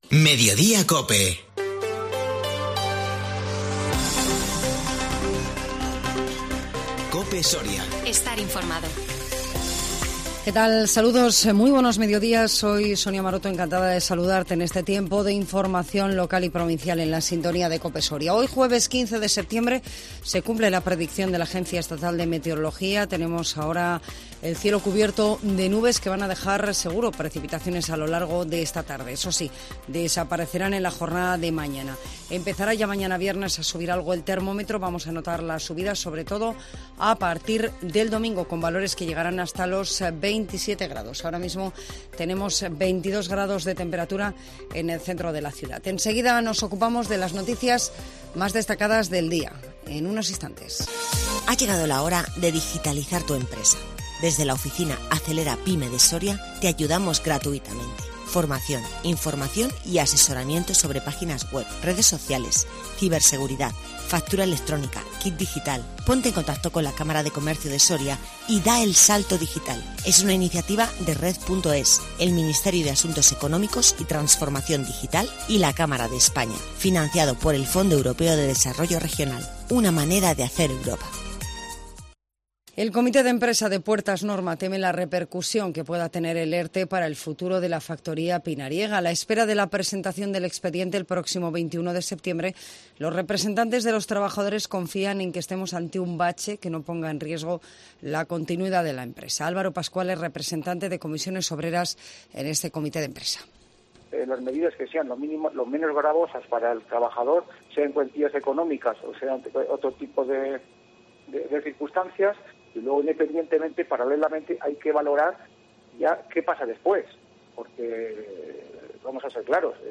INFORMATIVO MEDIODÍA COPE SORIA 15 SEPTIEMBRE 2022